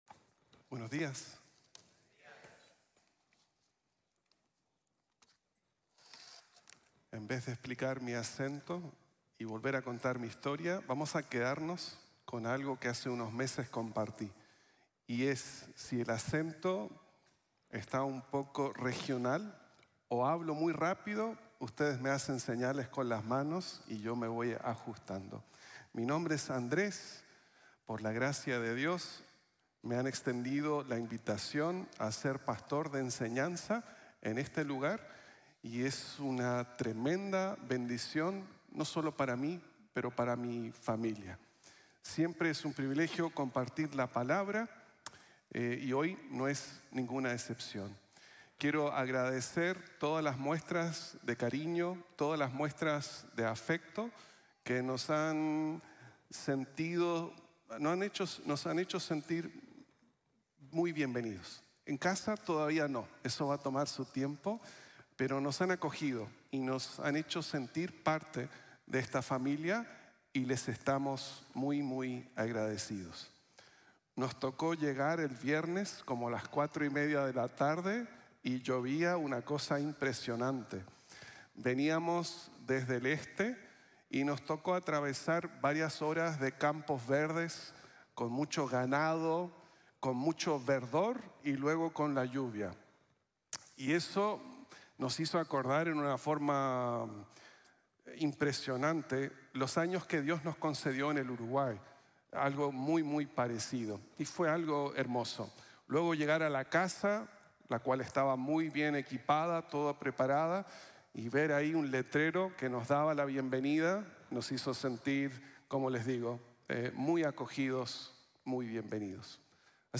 Ven y Compruebalo Tu Mismo | Sermon | Grace Bible Church